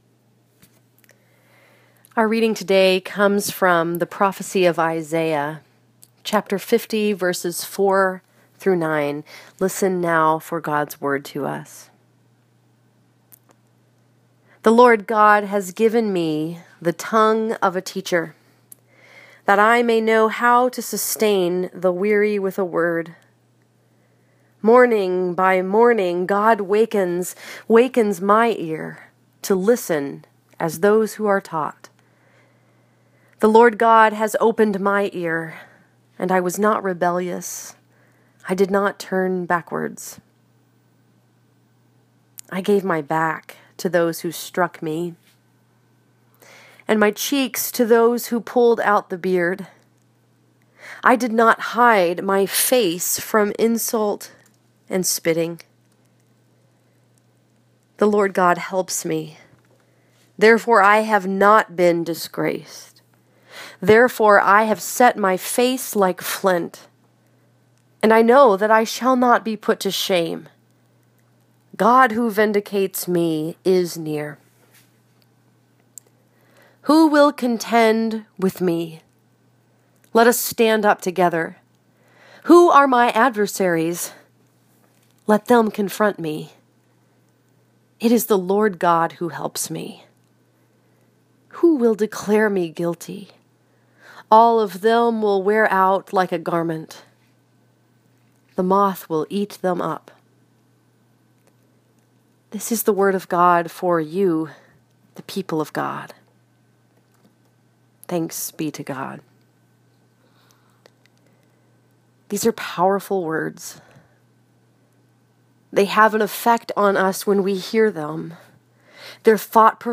sustaining-word.m4a